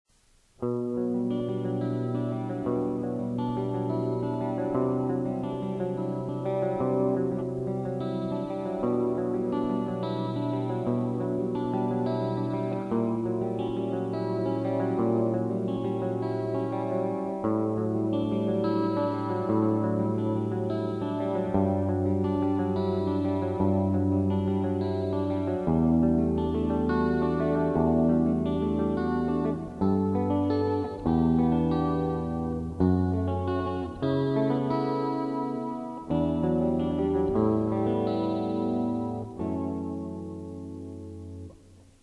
今日はもの悲しいアルペジオが生まれたなあ。
感想　得意のマイナーアルペジオですね(^o^)。